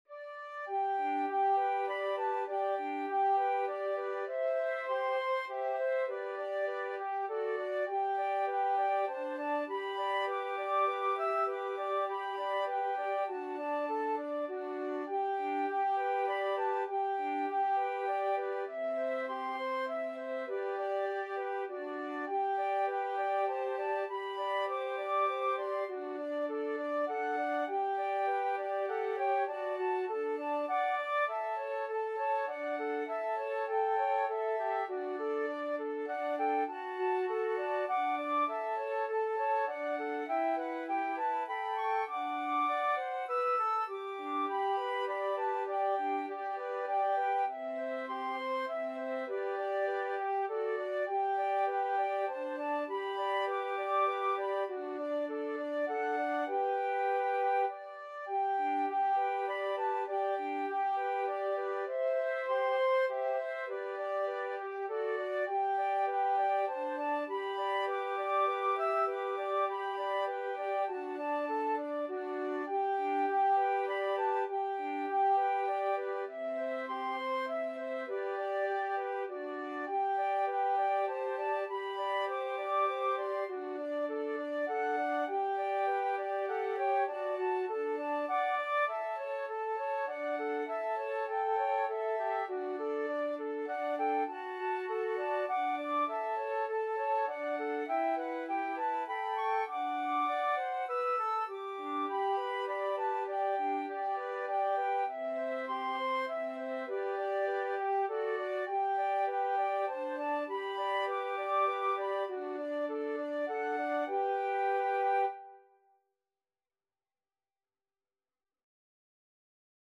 Traditional Music of unknown author.
G major (Sounding Pitch) (View more G major Music for Flute Trio )
Moderato
3/4 (View more 3/4 Music)
Flute Trio  (View more Easy Flute Trio Music)
Classical (View more Classical Flute Trio Music)